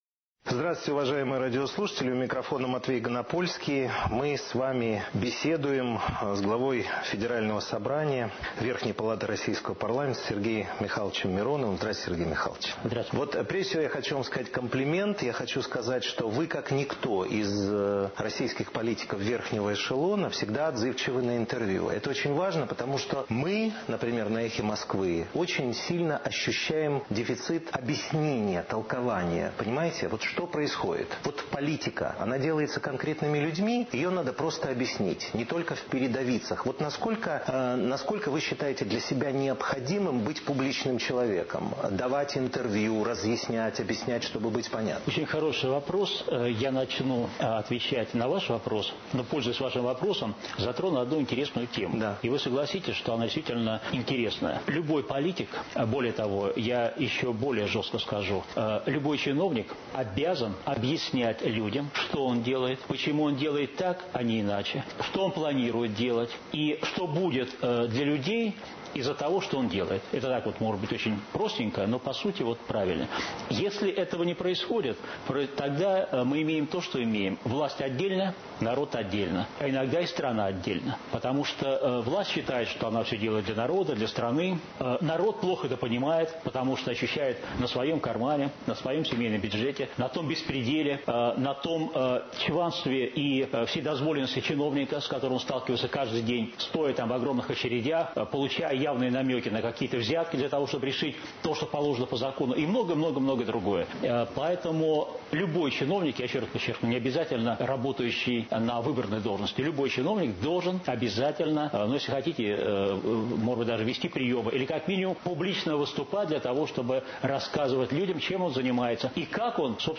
Светлана Сорокина: передачи, интервью, публикации
В КРУГЕ СВЕТА программа Светланы Сорокиной на радио «Эхо Москвы» 4 ноября 2006 г. Гость - Сергей Миронов . (Выпуск без участия Светланы Сорокиной; ведущий – М. Ганапольский) рубрикатор : Аудио: эфир – .mp3, 47:28, 8 349 Кб .